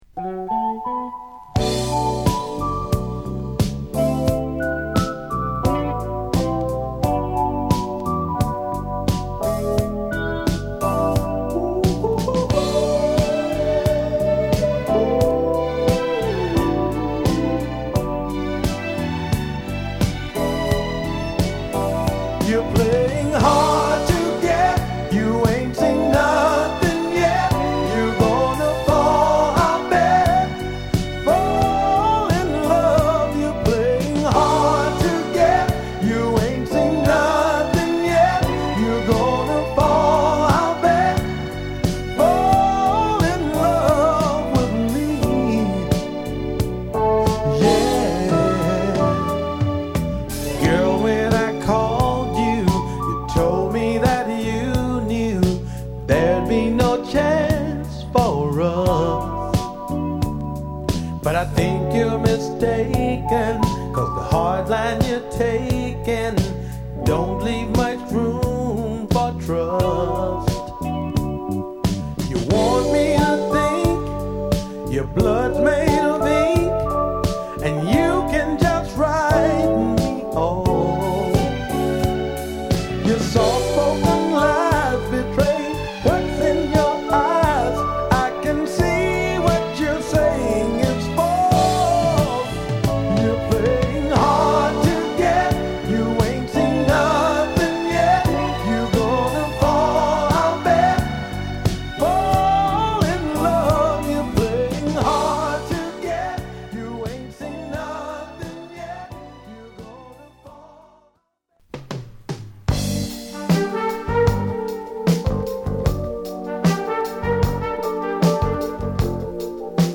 勿論この時代らしいブラコン〜ディスコサウンドだが、オススメは靭やかなメロウ・グルーヴA3
緩やかなミディアムスロウのトラックにソウルフルな歌声が素晴らしい1曲！
黒さ際立つブギーチューンB4